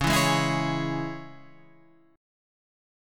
C# Minor